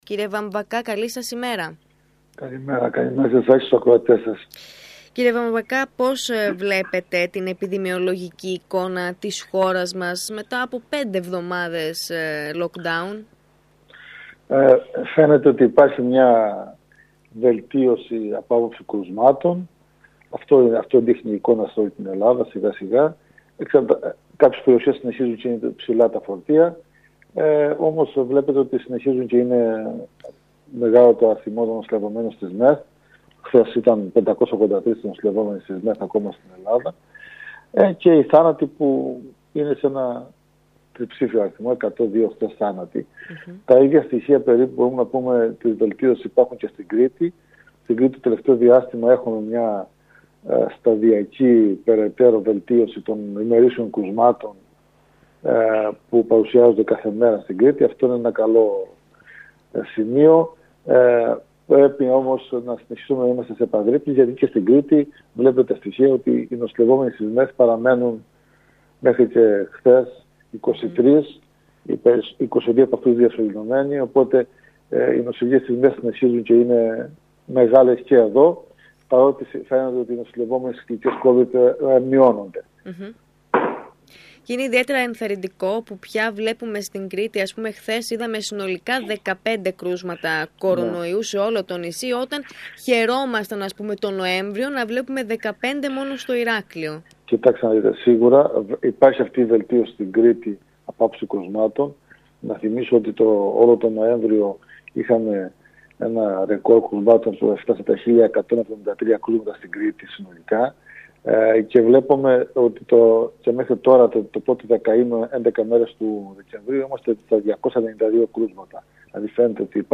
Την επιδημιολογική εικόνα της χώρας και της Κρήτης, μετά από πέντε εβδομάδες lockdown, σχολίασε ο Αντιπεριφερειάρχης Δημόσιας Υγείας Λάμπρος Βαμβακάς, μιλώντας στον ΣΚΑΪ Κρήτης 92,1